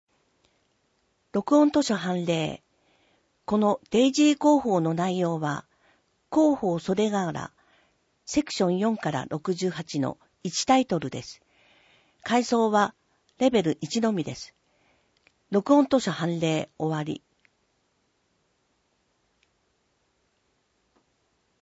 目の不自由な人などのために録音されたデイジー図書を掲載しています。